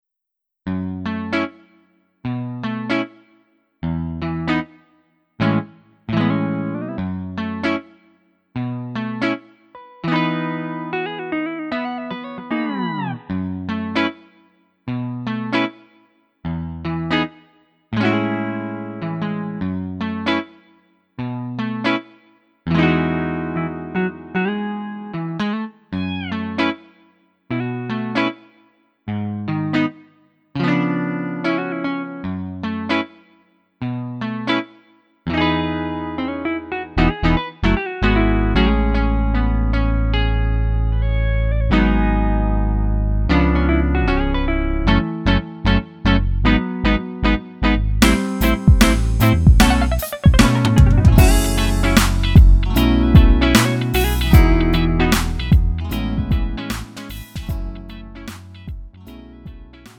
음정 원키 3:53
장르 구분 Lite MR